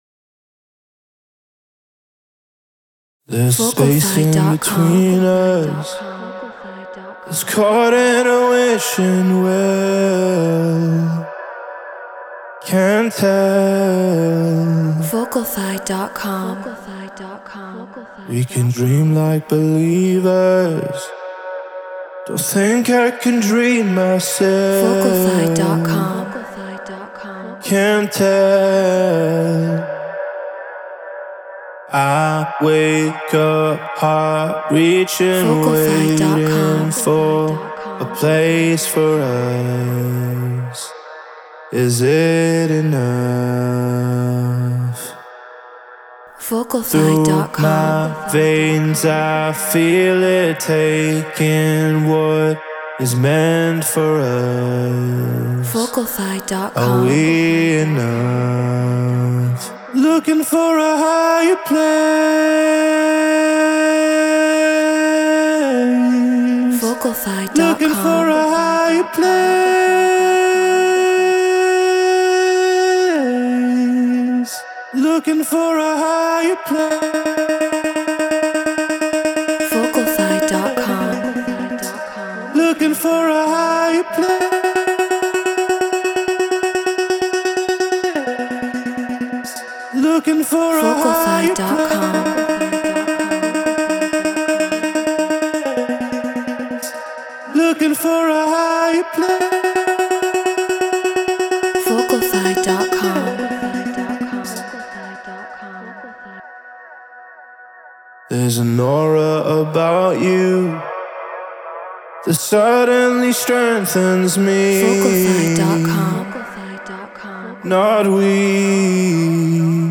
Stutter House 127 BPM Bmaj